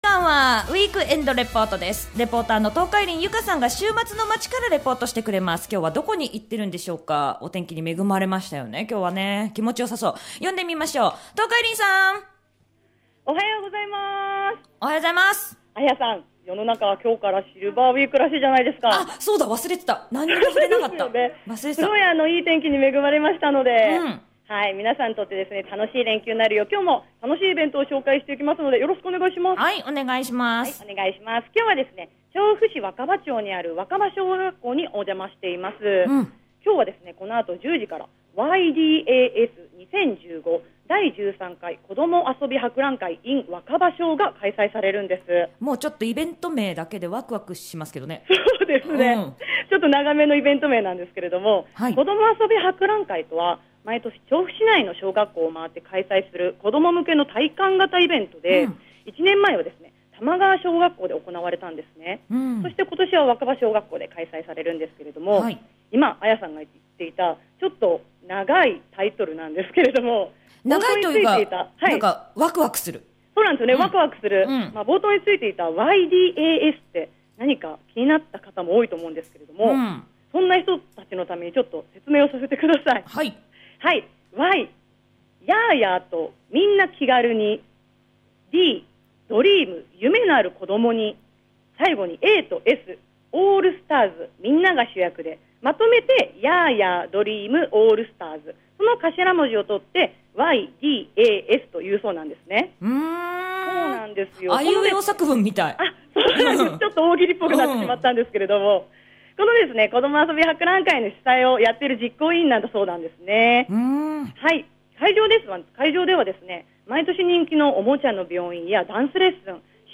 調布市若葉小学校でこどもあそび博覧会が開催されたんですー！